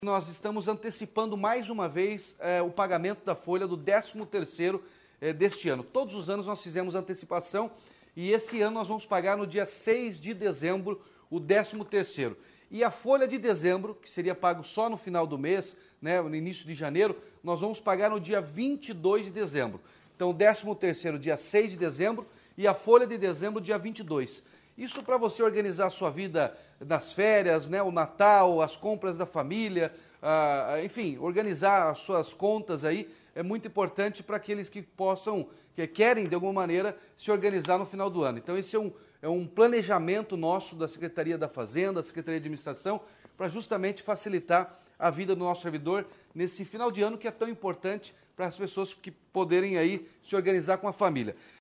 Sonora do governador Ratinho Junior sobre o adiantamento do pagamento do 13º salário dos servidores públicos estaduais